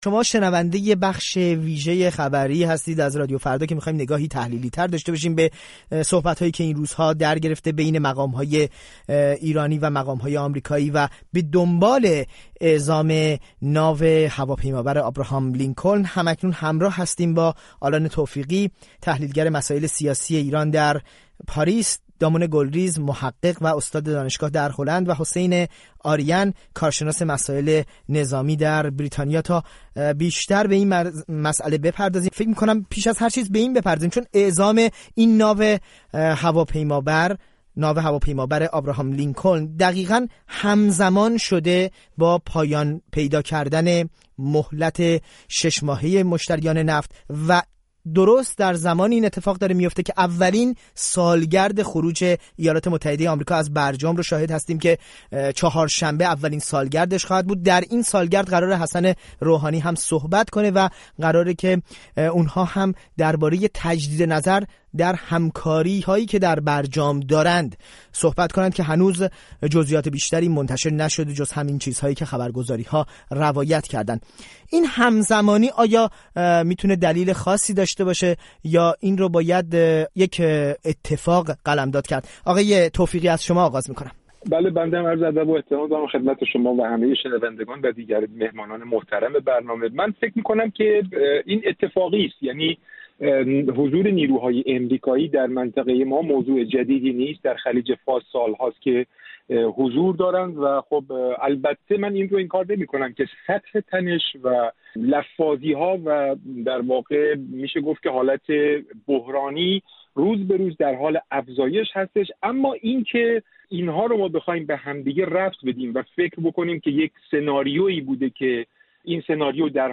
میزگرد رادیو فردا درباره افزایش تنش بین ایران و آمریکا با حضور سه تحلیلگر سیاسی و نظامی